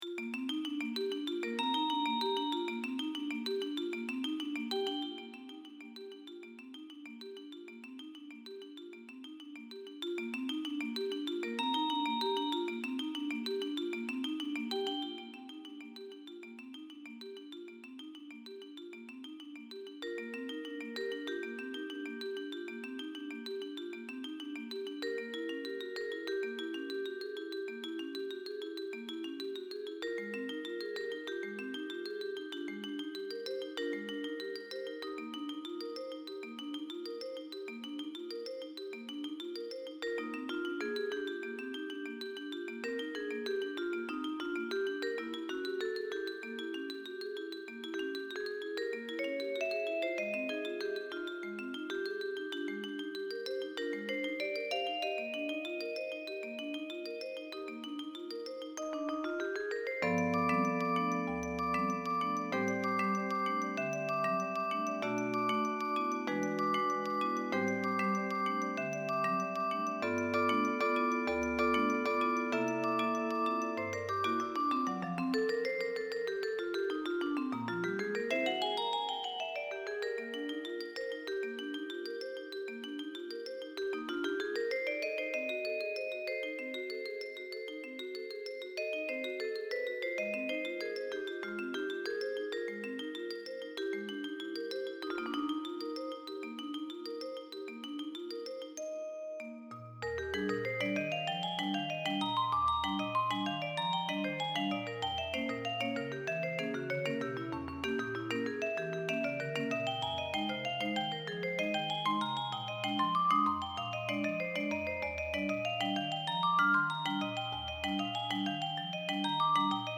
Voicing: Mallet Duet